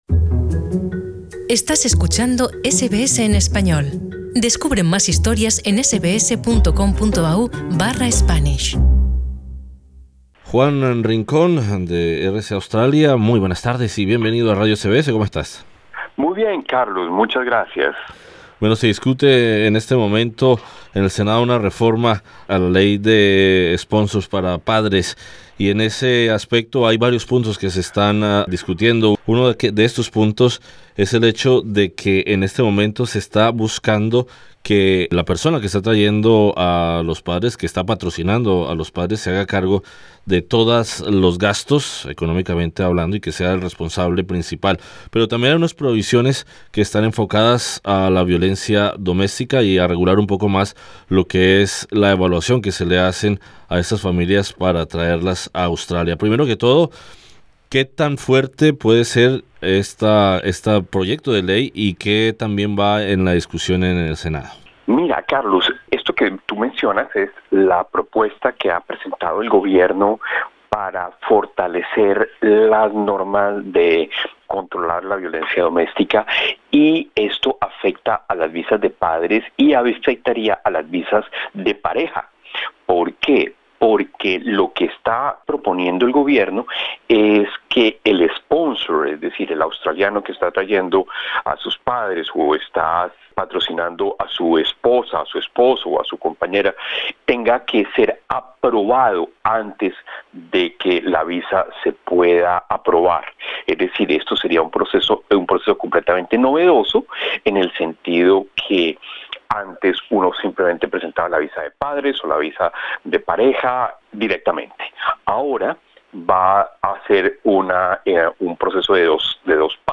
Para analizar el impacto de estos posibles cambios, conversamos con el agente de inmigración